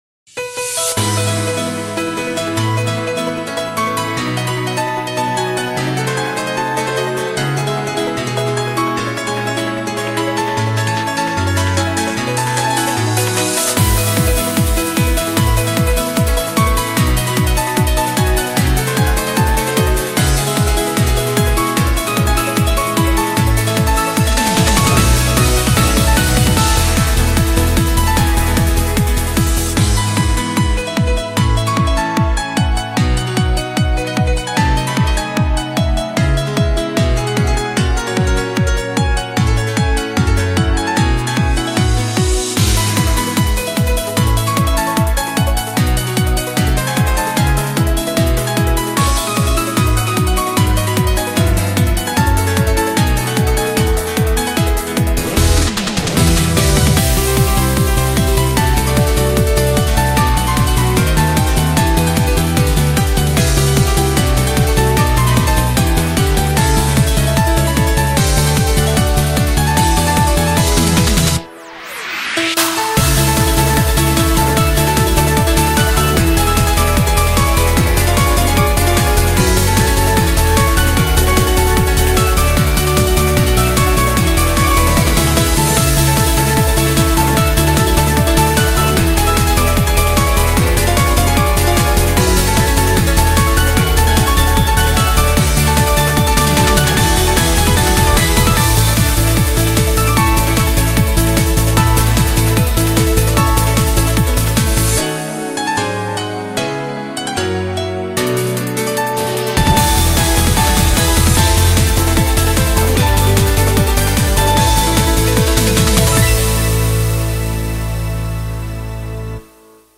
BPM150
Audio QualityPerfect (Low Quality)